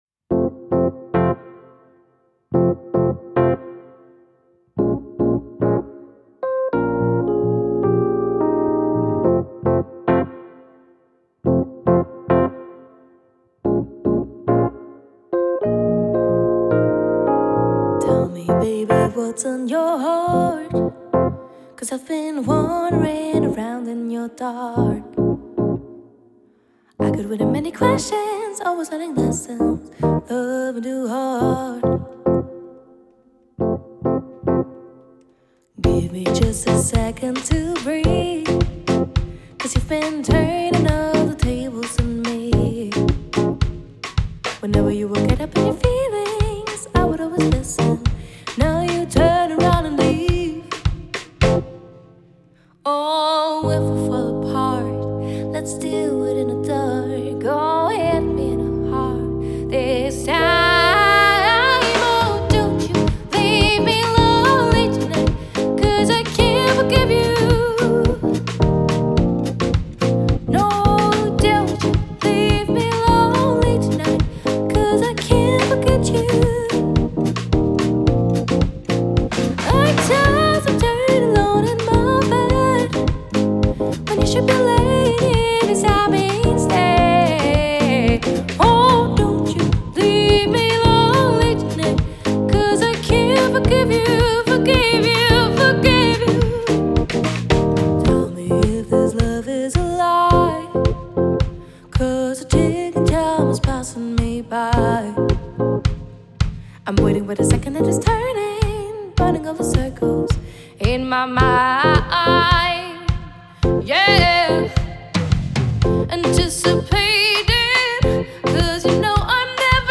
Gesang, Bandleaderin
Klavier
E-Bass, Kontrabass
Schlagzeug